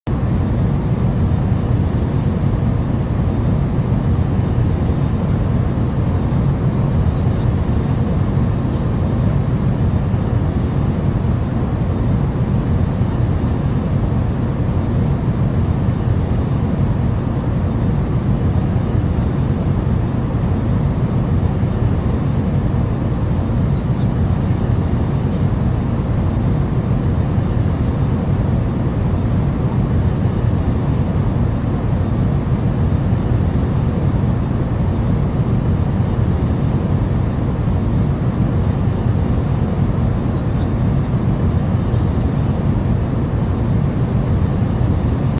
KDFW-KATL Hurricane Idalia Arrival A32NX ROUGH Landing PART 1